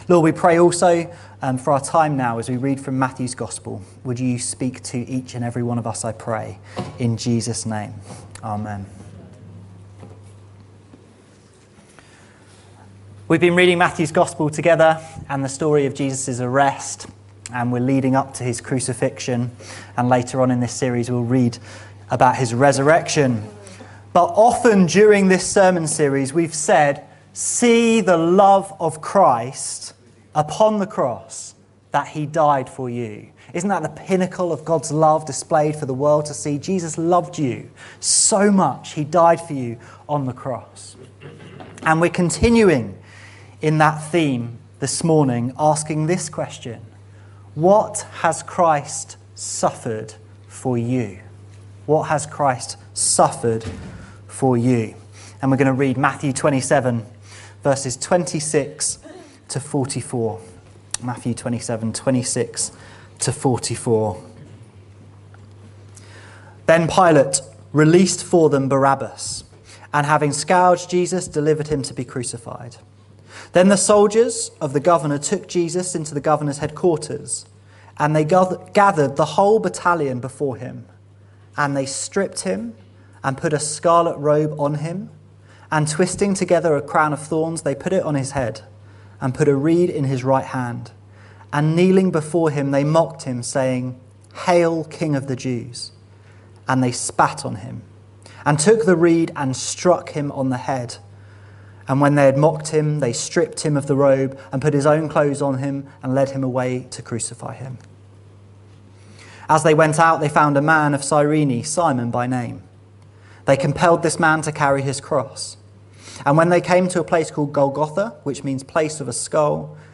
But this sermon looks at the cross from the perspective of Jesus taking on our shame for his honour and glory.